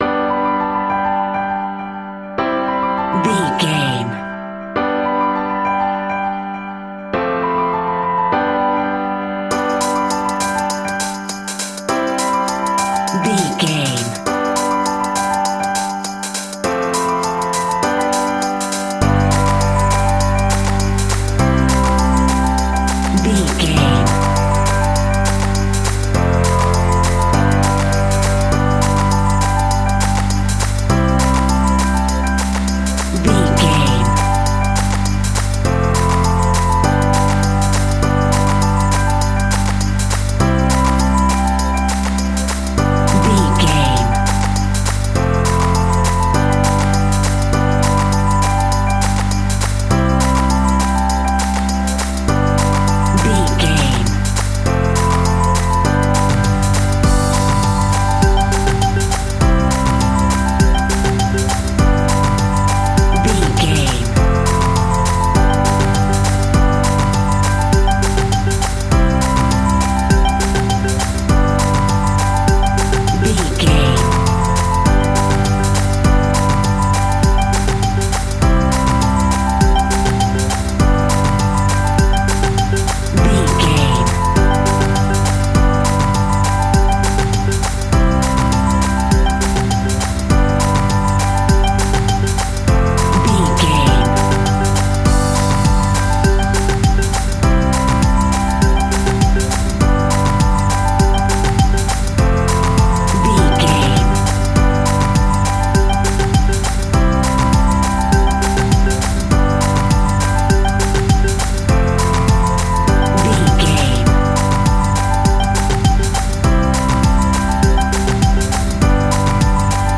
Ionian/Major
D
pop rock
indie pop
energetic
uplifting
catchy
upbeat
acoustic guitar
electric guitar
drums
piano
organ
bass guitar